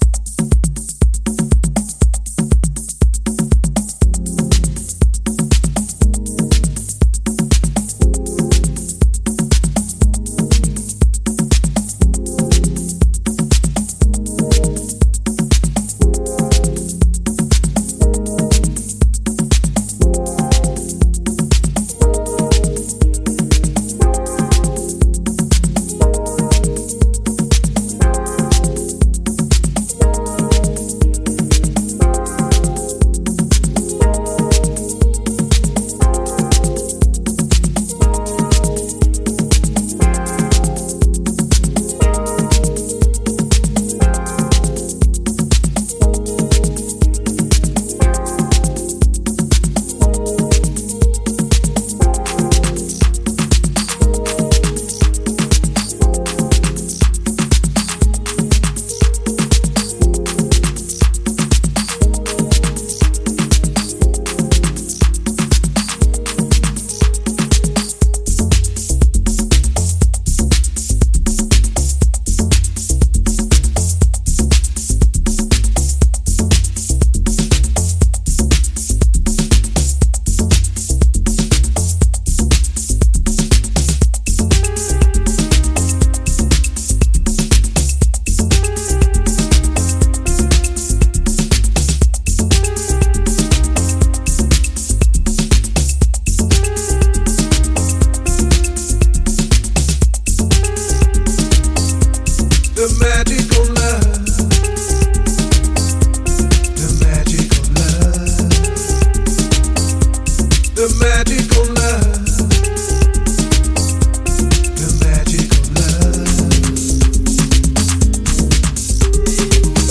French deep house